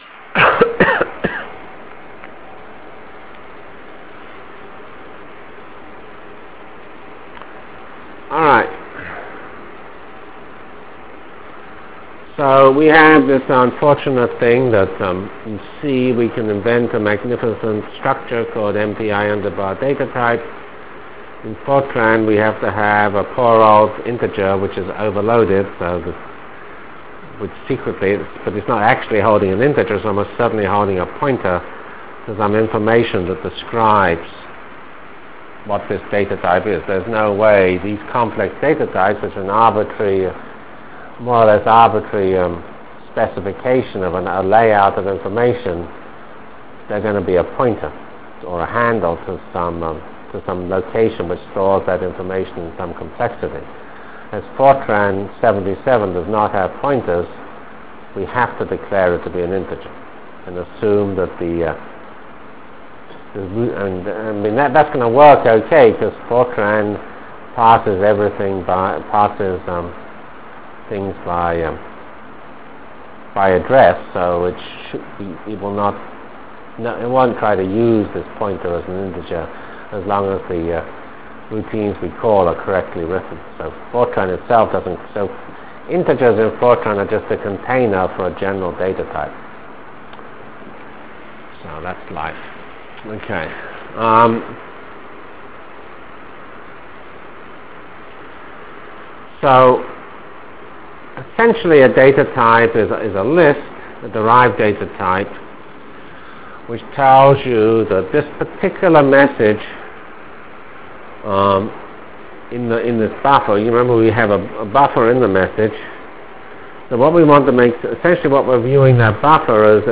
From CPS615-Completion of MPI foilset and Application to Jacobi Iteration in 2D Delivered Lectures